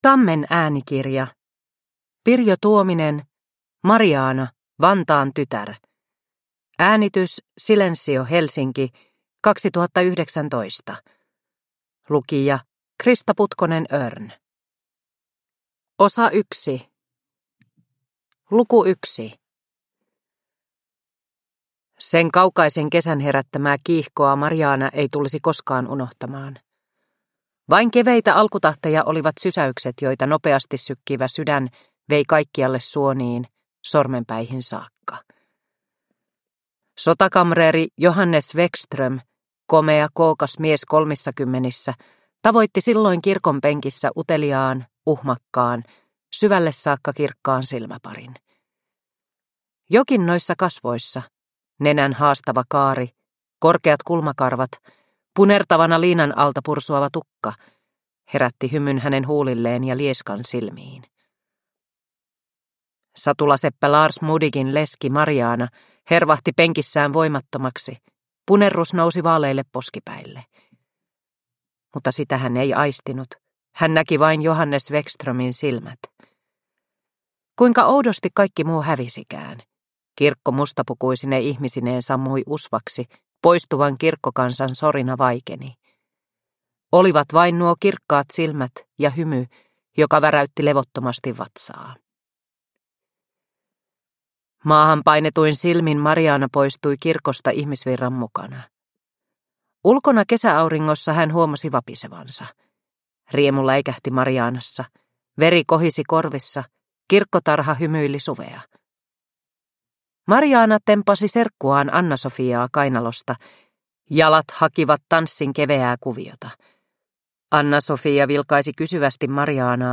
Mariaana, Vantaan tytär – Ljudbok – Laddas ner